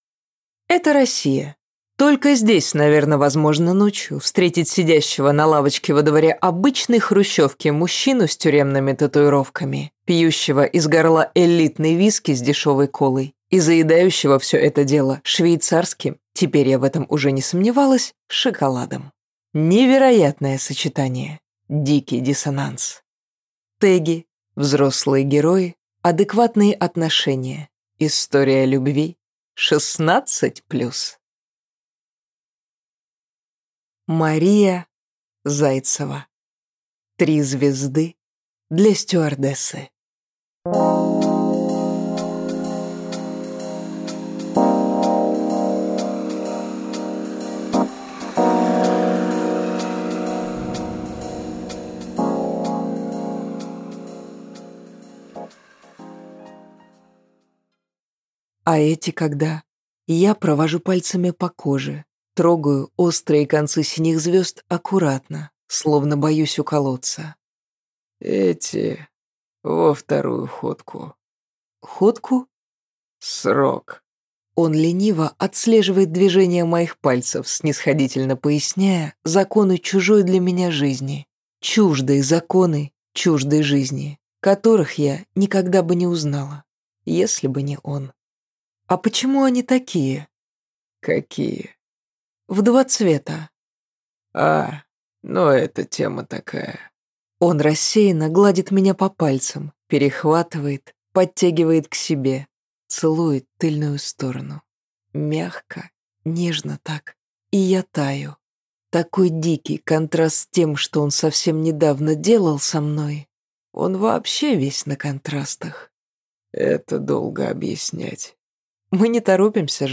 Аудиокнига «Три звезды» для стюардессы | Библиотека аудиокниг